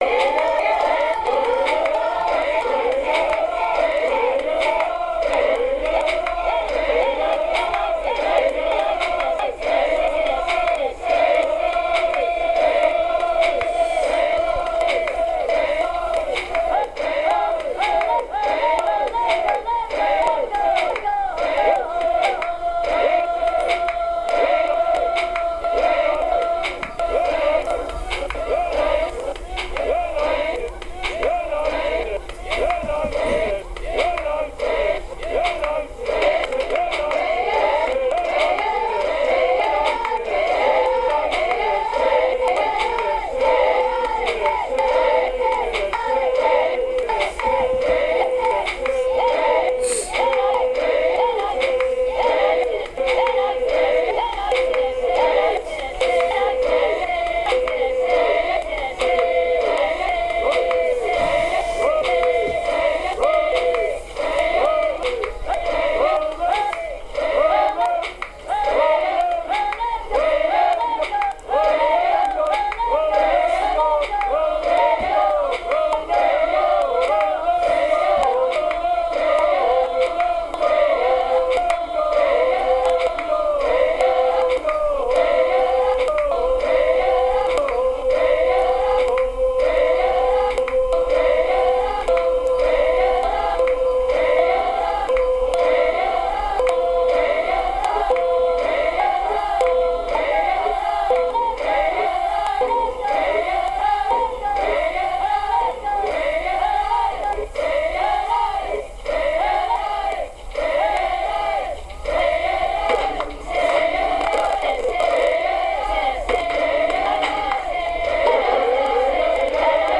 (All the sound in the finished piece derives from the 12 second field recording, just to be clear.) The first version was played at "live speed", the second was played speeded up and the third slowed down. In all 3 instances granulation was applied so that the sample took more time than 12 seconds to play through, and 5 minutes (near enough) of each version was recorded.
All were played at the same volume on the Microgranny via an attached speaker - a cardboard Phone speaker that only has an on/off switch. The three tracks were then mixed together on the Boss and mastered.